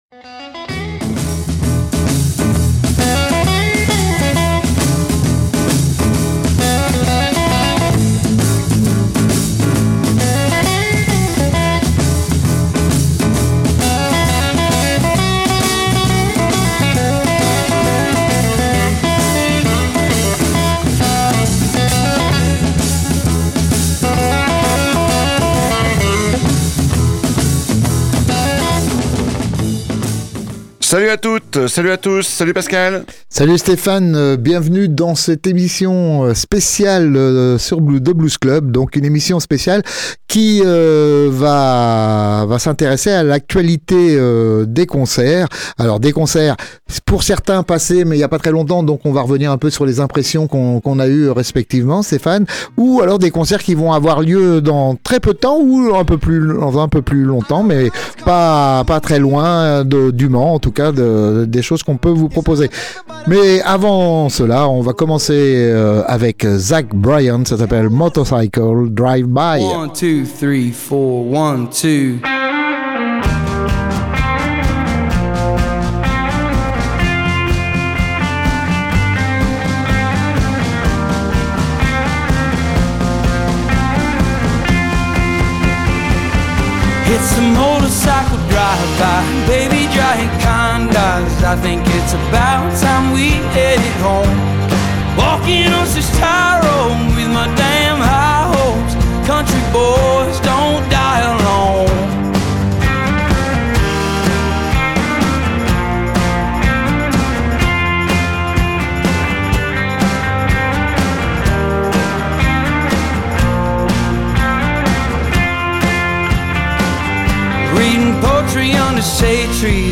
blues-club-466-speciale-concerts.mp3